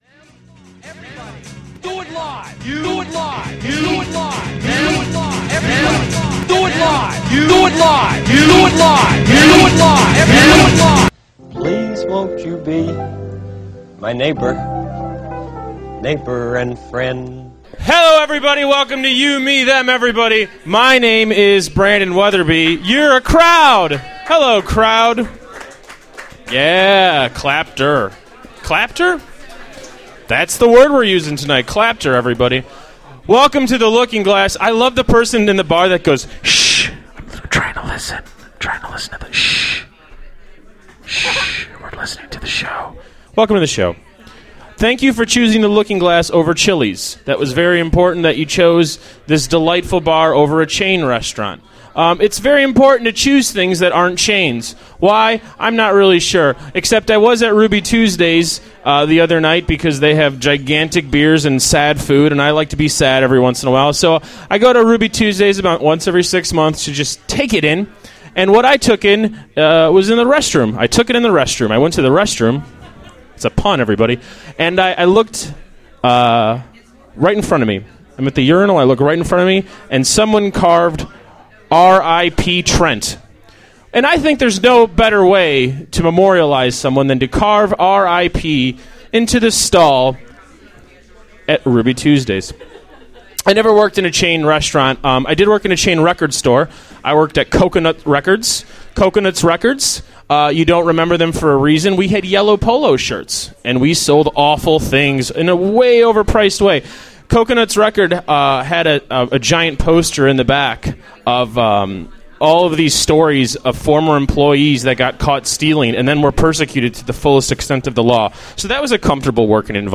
Stripmall Ballads closed the show with an excellent set.
Talk show!